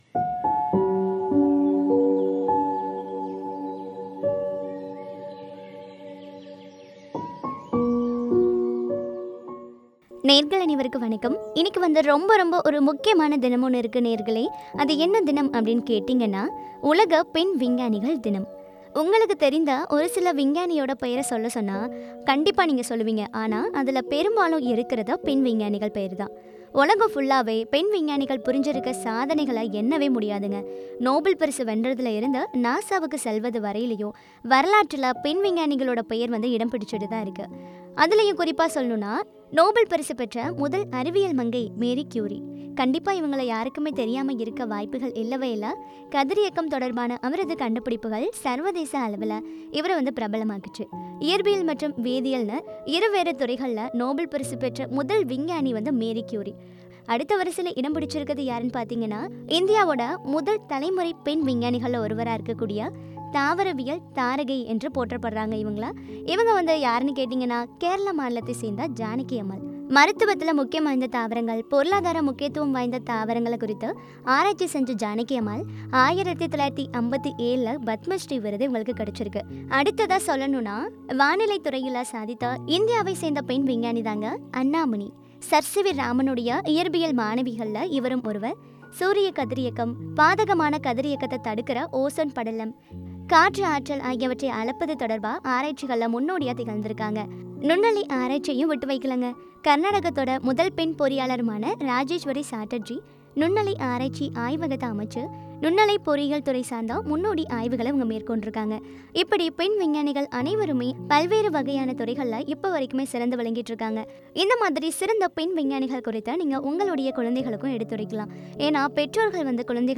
கண்டுபிடிப்புகளும் பற்றிய உரையாடல்.